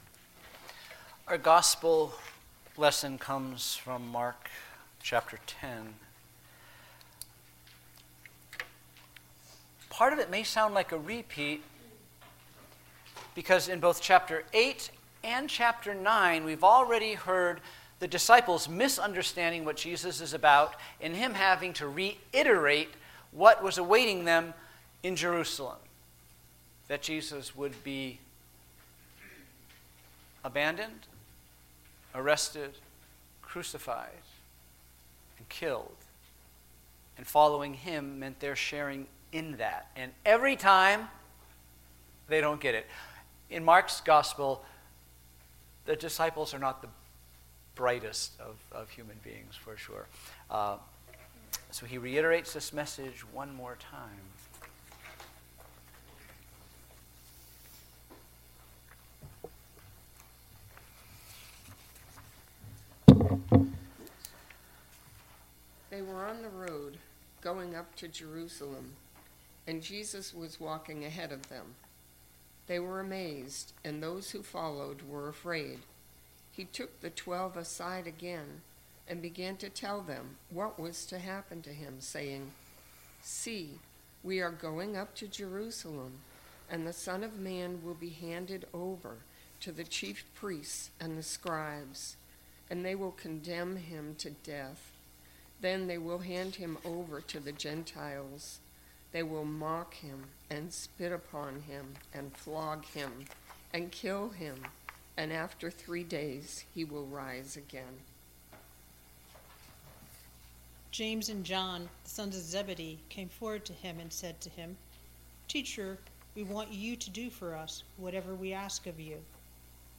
Sermons Tourist or Pilgrim?